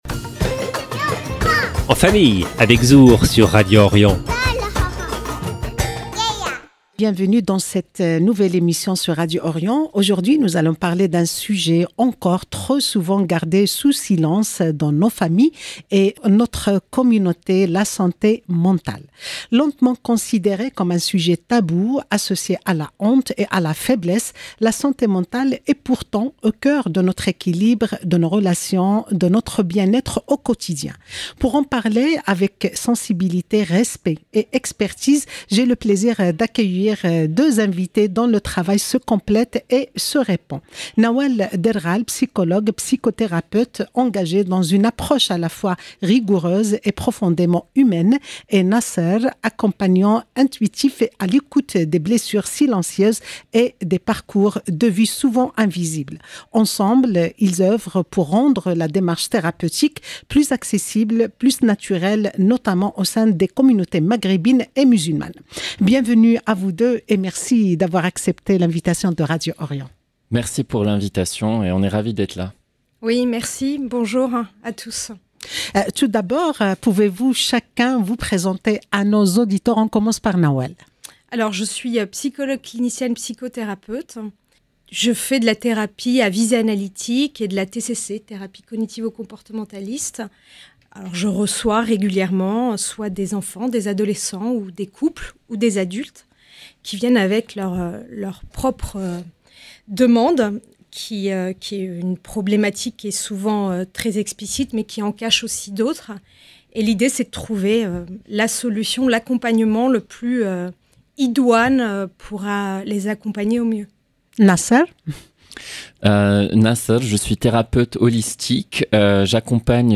Pour en parler avec sensibilité, respect et expertise, j’ai le plaisir d’accueillir deux invités dont le travail se complète et se répond :